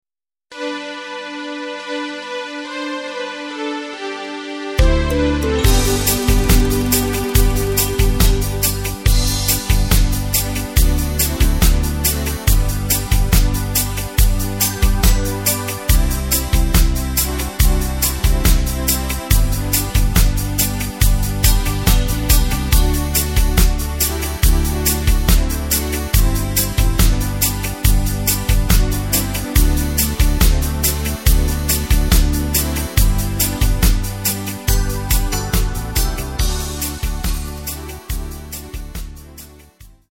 Takt:          4/4
Tempo:         140.50
Tonart:            C
Schlager aus dem Jahr 1984!
Playback mp3 Mit Drums